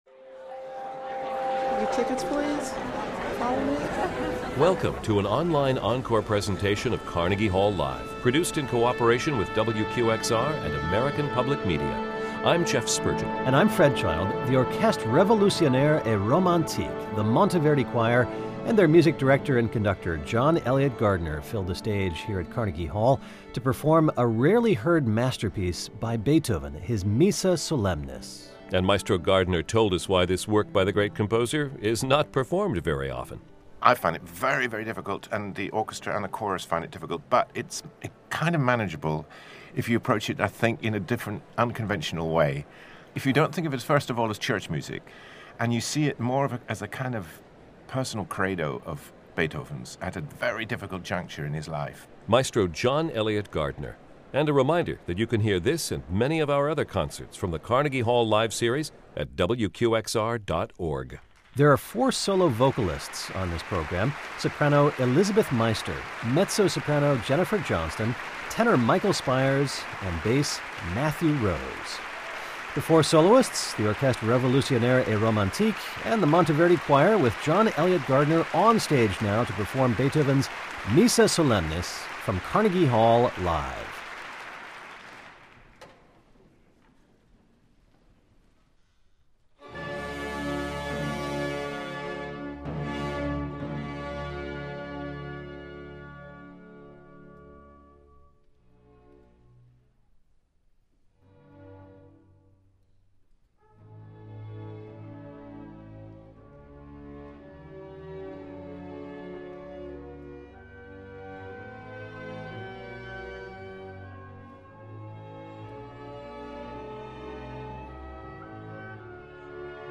The celebrated English conductor leads his Orchestre Revolutionaire et Romantique and Monteverdi Choir in a performance of one of Beethoven's most expansive and searching works: the Missa Solemnis.
John Eliot Gardiner leads the Orchestre Revolutionnaire et Romantique and the Monteverdi Choir at Carnegie Hall in New York, NY on November 17, 2012.